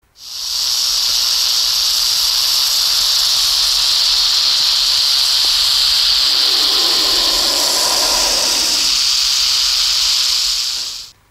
Голоса природы (400)
Правильный ответ: Crotalinae, гремучея змея («судя по звуку, не меньше 7 лет зверюшке»)